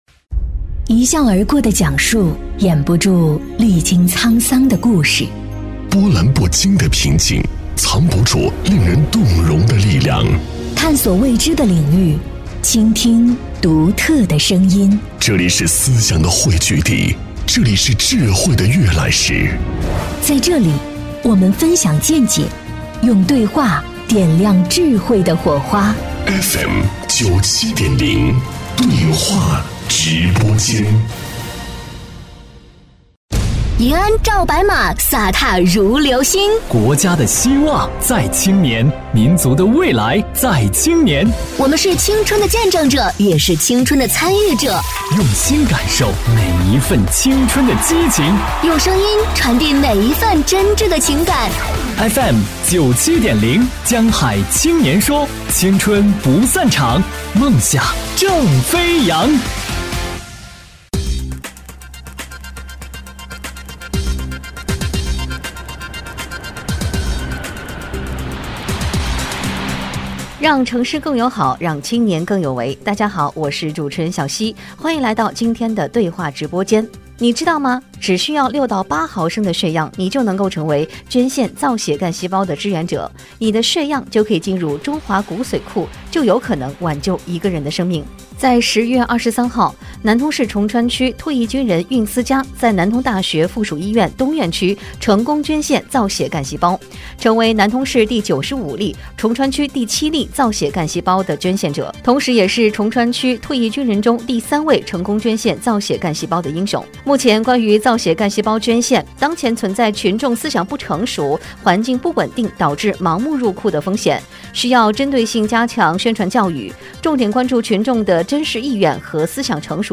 “江海青年说”造血干细胞捐献者访谈.mp3